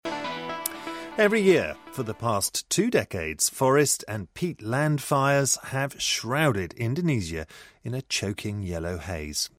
【英音模仿秀】印度尼西亚森林大火 听力文件下载—在线英语听力室